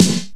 EMX SNR 3.wav